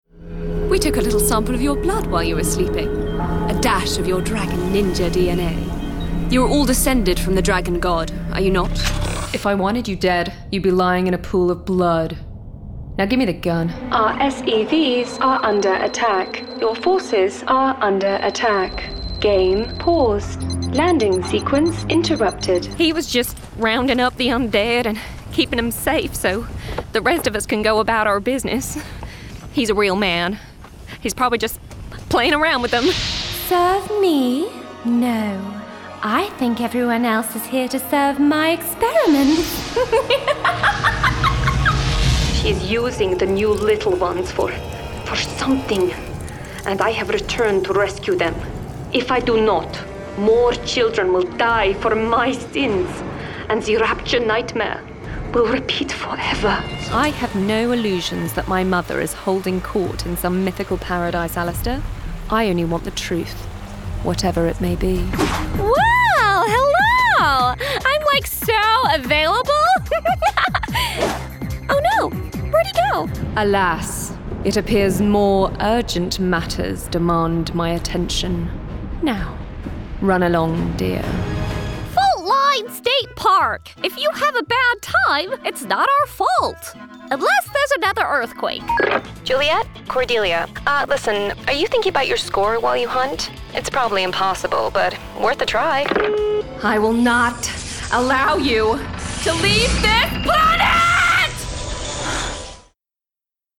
Gaming and Animation. Characters, Amusing
Very versatile.
Gender Female